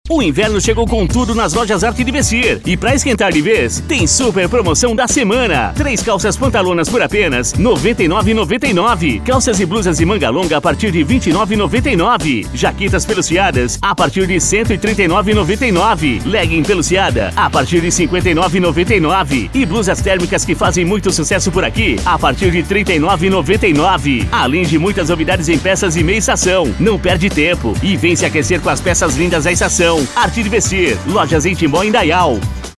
SPOT ARTE DE VESTIR:
Impacto
Animada